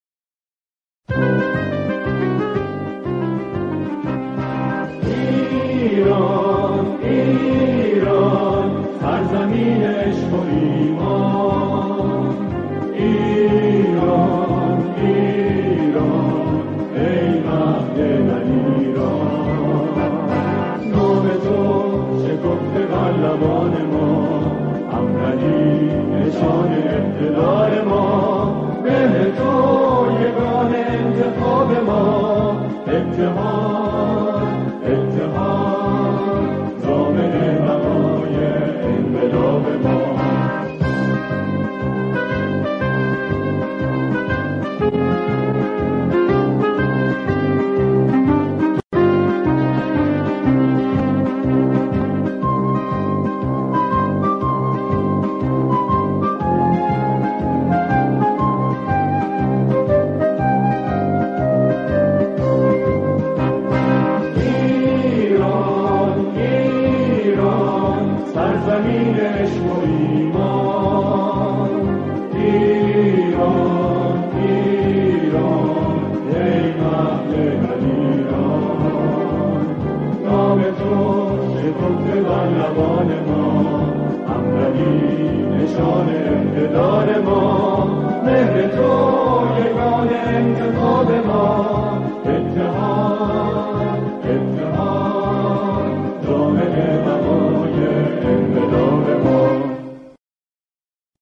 با خوانندگی گروهی از جمعخوانان اجرا شده.